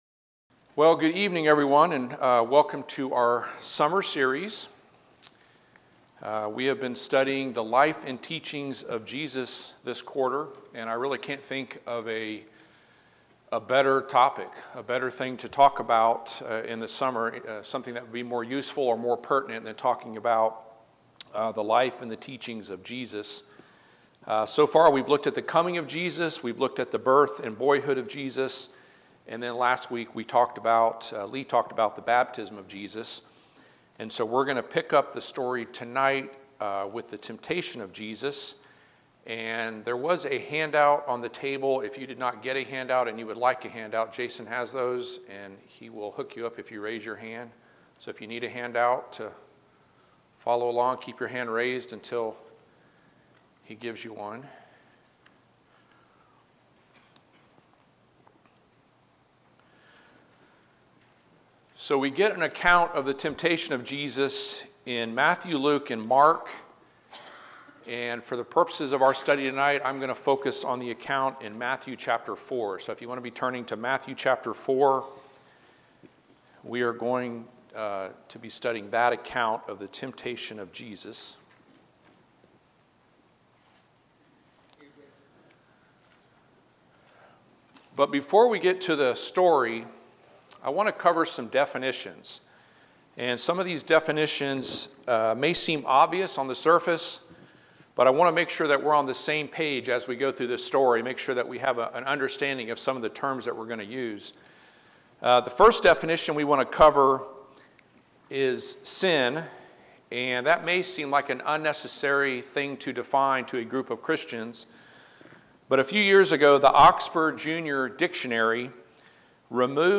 Summer 2022 The Life and Teachings of Jesus Service Type: Midweek Bible Class « John Baptizes Jesus Sermon on the Mount